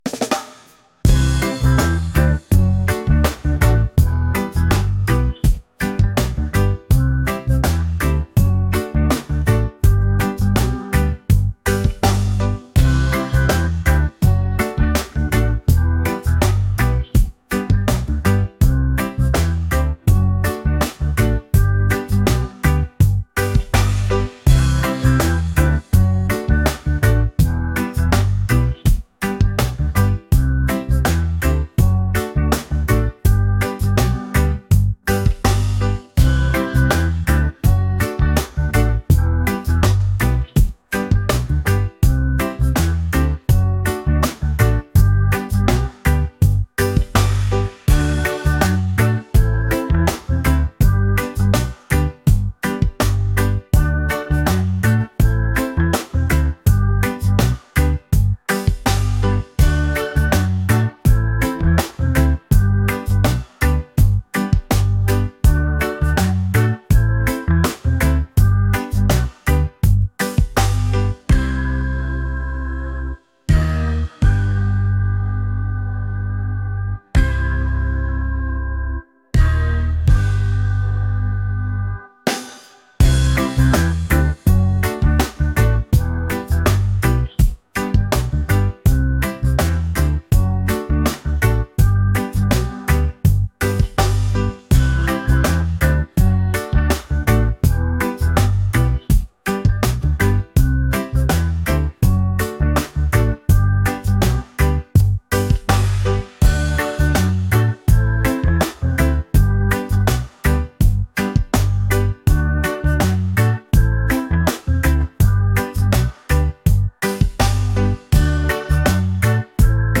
reggae | laid-back | upbeat